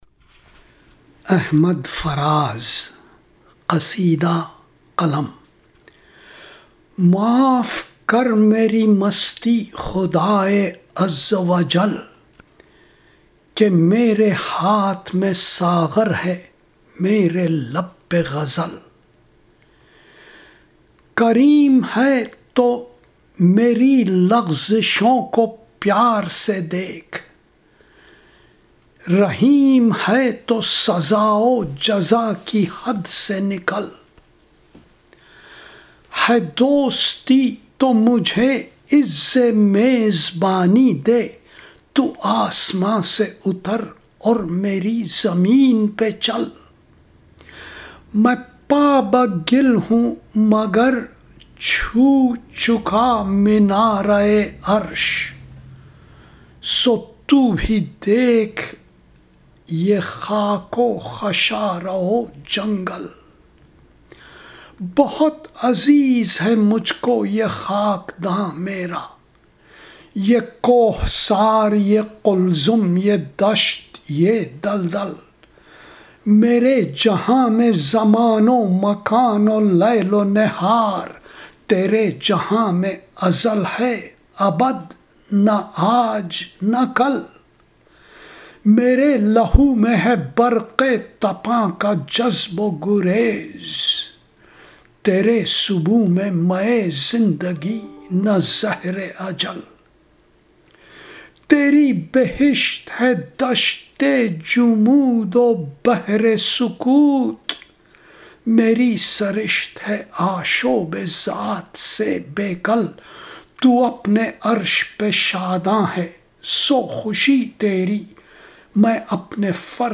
Recitation
It is posted on youtube as a recitation by faraz at a private gathering.
The audio, both ahmad faraz and my recitation is also posted in corresponding segments for easy listening.